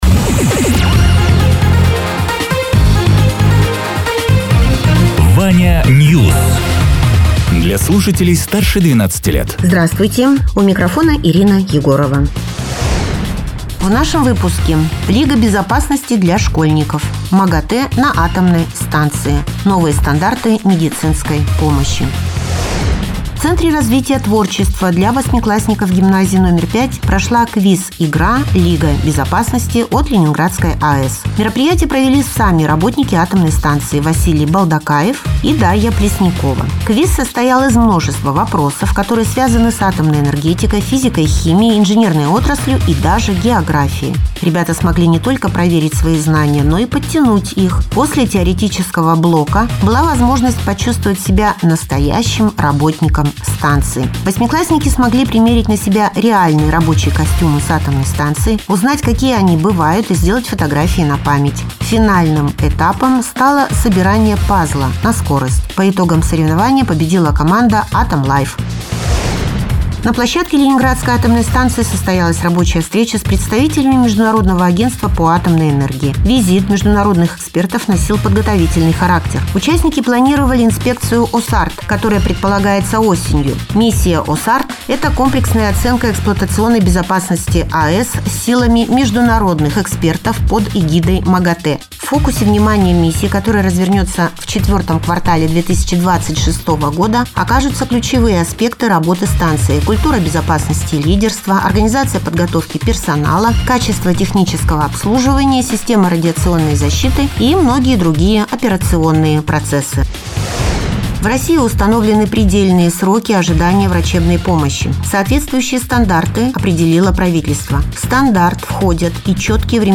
Радио ТЕРА 04.02.2026_12.00_Новости_Соснового_Бора